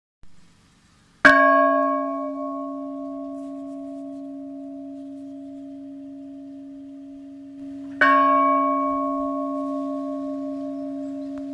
cloche n°2 - Inventaire Général du Patrimoine Culturel